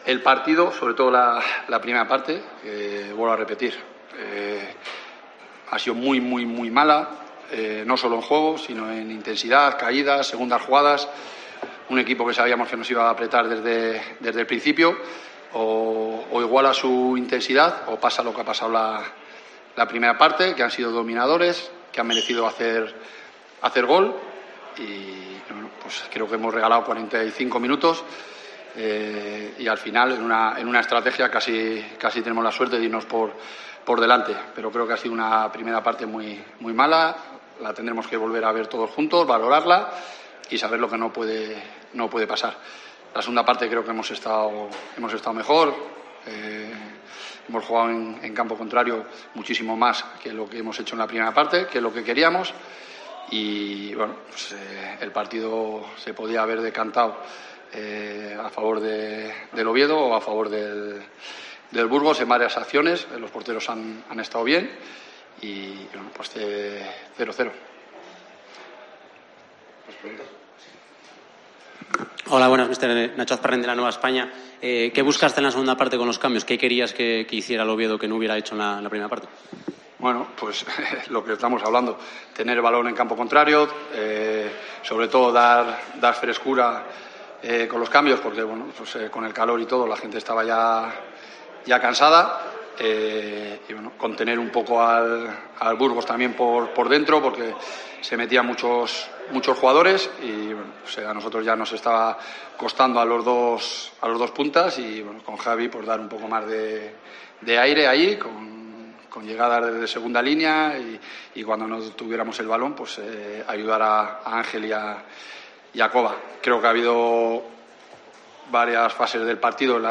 RUEDA DE PRENSA - OVIEDO
Rueda de prensa Bolo (post Burgos)